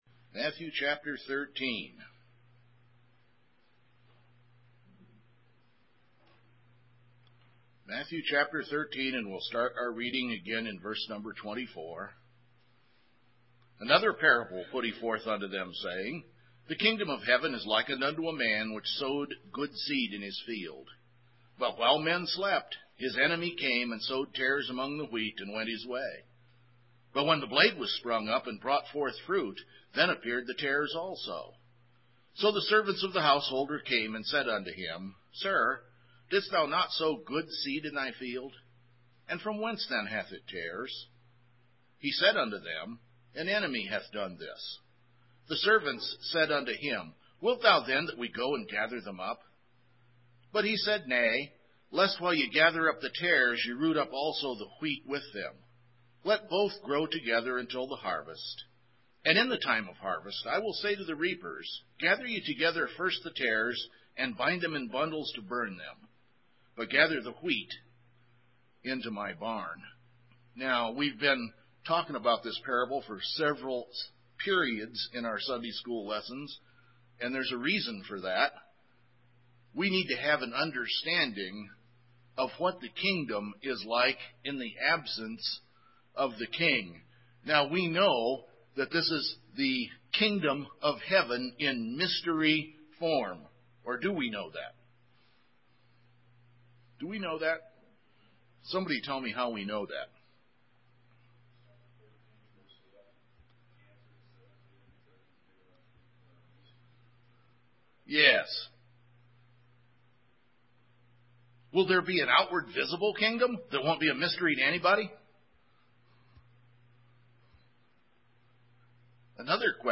A Non-Denominational Bible Church in Black Forest, Colorado